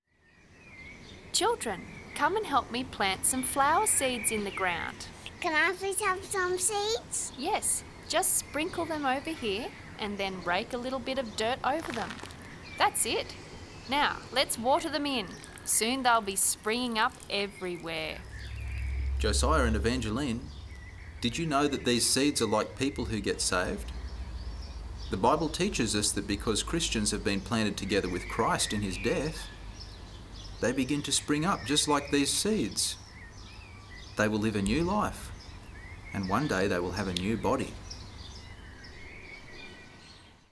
32-Planted-Together-dialogue.mp3